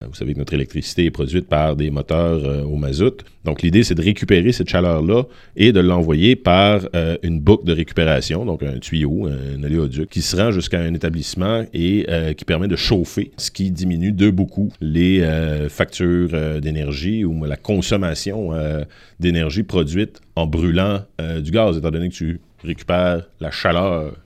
Les explications du maire Antonin Valiquette: